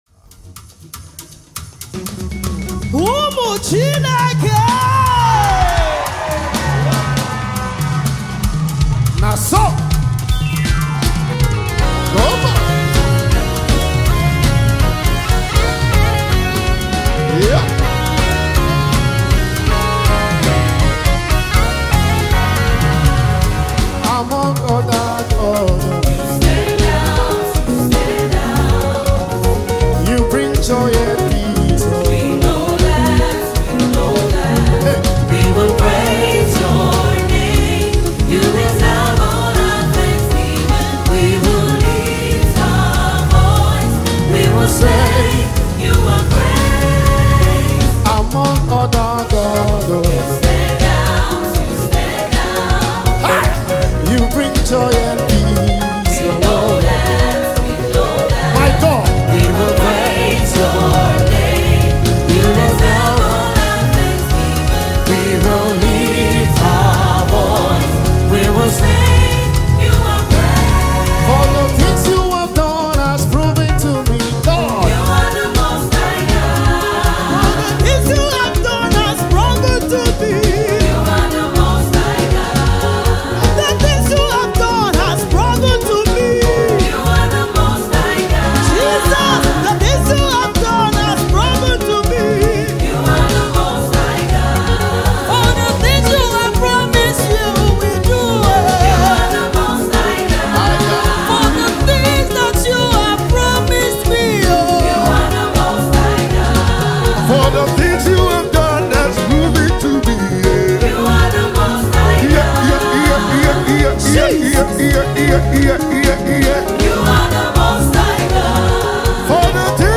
Already creating buzz in gospel music circles